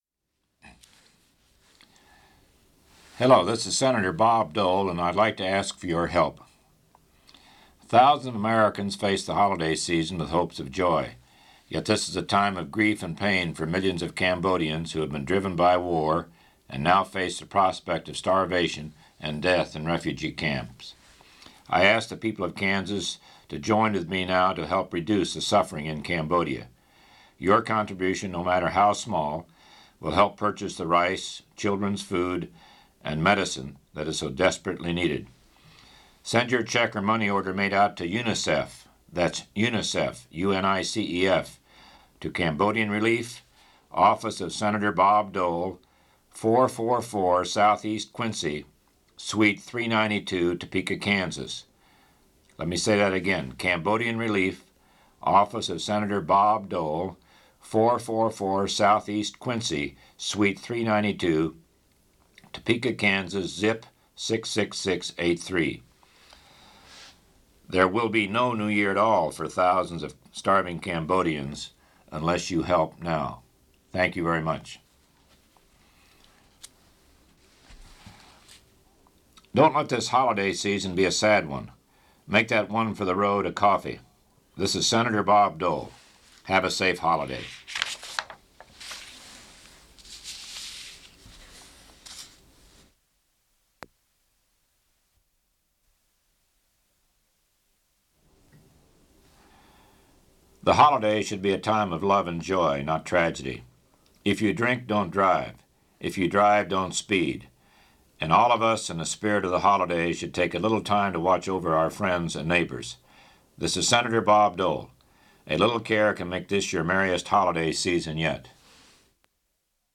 Part of Radio PSAs: Cambodian Refugees and UNICEF; Don't Drink and Drive